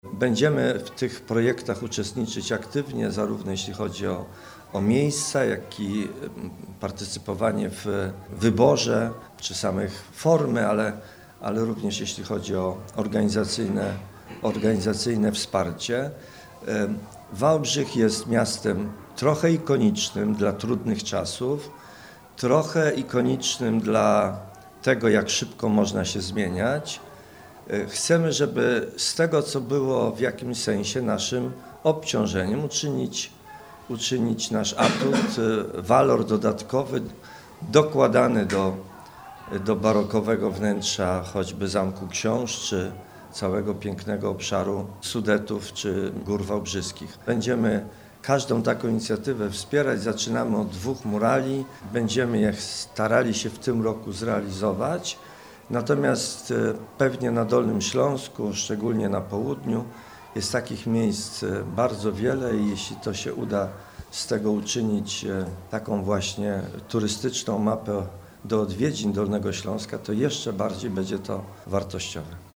W ramach inauguracji Dolnośląskiego Szlaku Filmowego w Wałbrzychu powstaną dwa murale na miejskich kamienicach, mówi Roman Szełemej.